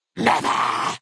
10 KB Category:Fallout: New Vegas creature sounds 1
FNV_GenericFeralGhoulAttack_Never.ogg